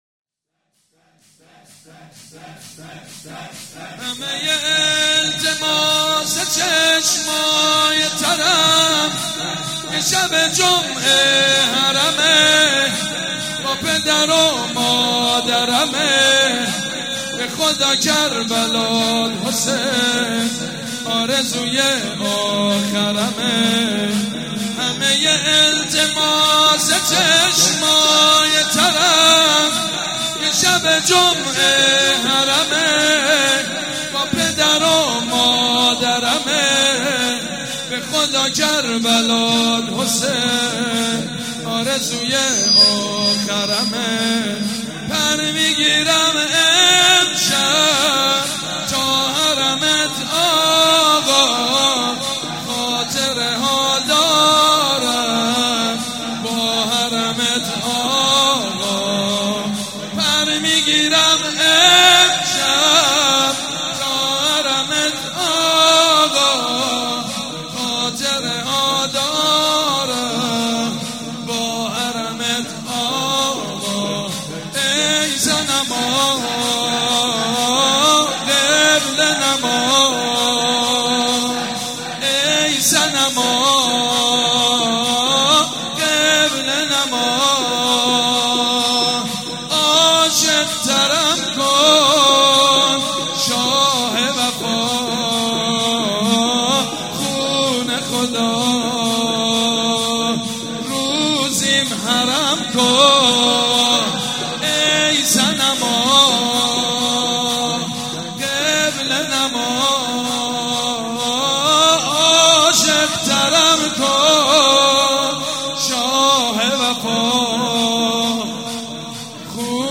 مناسبت : وفات حضرت زینب سلام‌الله‌علیها
مداح : سیدمجید بنی‌فاطمه قالب : شور